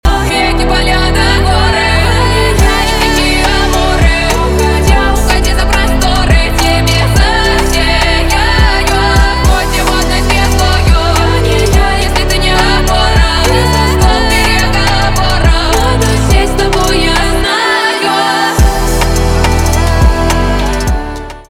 поп
красивые , битовые , басы , чувственные